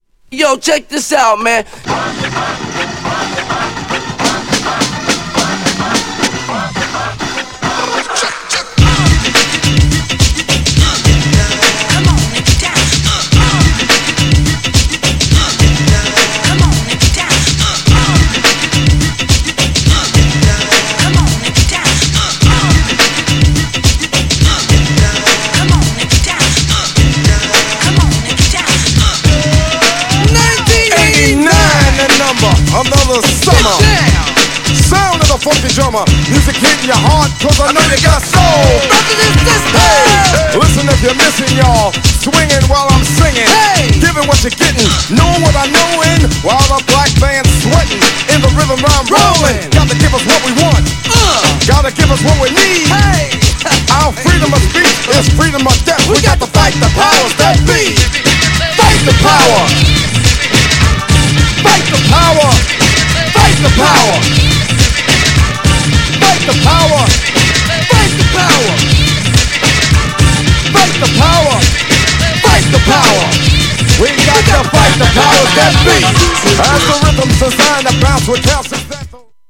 GENRE Hip Hop
BPM 131〜135BPM